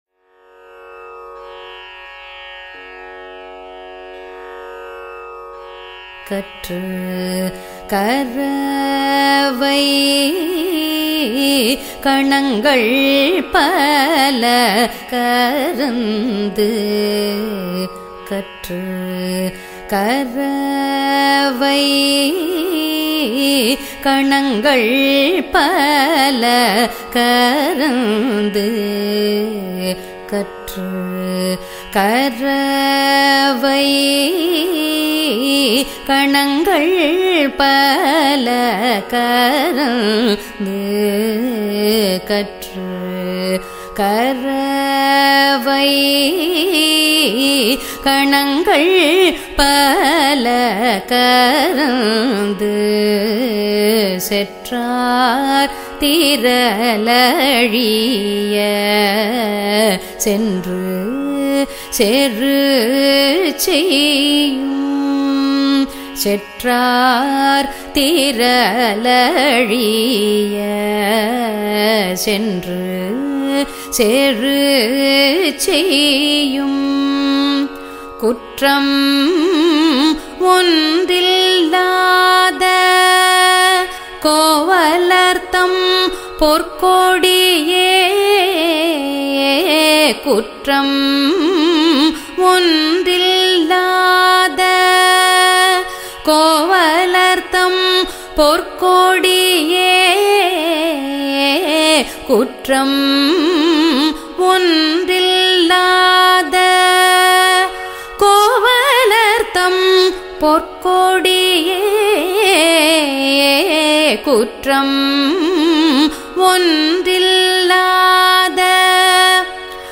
husEni
misra chAppu